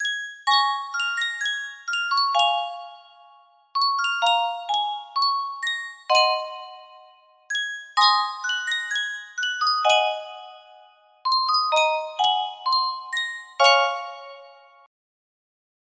Reuge Music Box - Under the Double Eagle 6416 music box melody
Full range 60